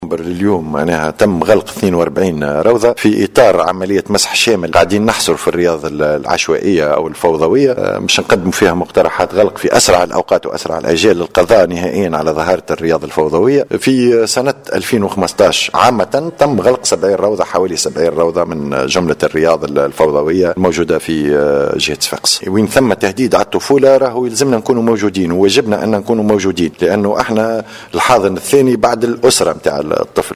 أكد المندوب الجهوي للطفولة بصفاقس، الهادي السويسي في تصريح لمراسل "الجوهرة أف أم" أنه تم غلق حوالي 70 روضة أطفال في الجهة وذلك منذ بداية سنة 2015.